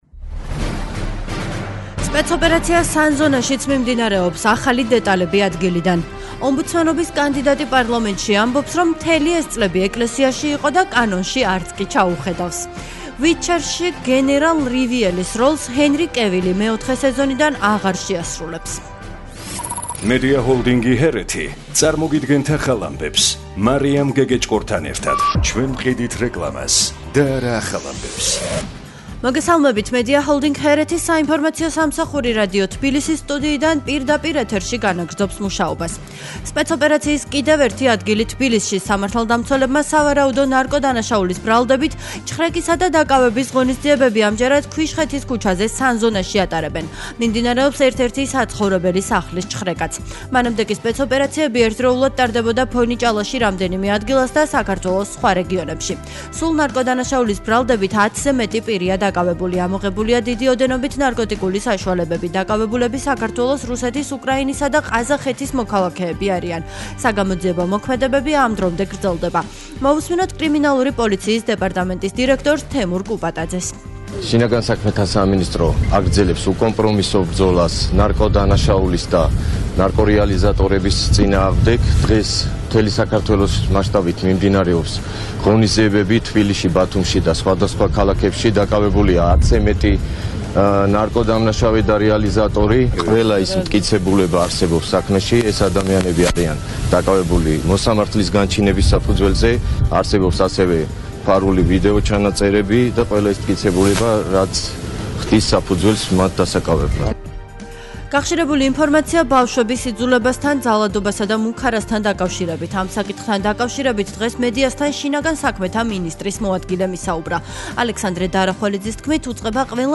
ახალი ამბები 16:00 საათზე